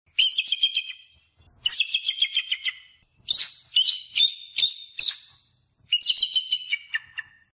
Звуки орла
На этой странице собраны разнообразные звуки орла: от пронзительных криков до низкого клекота.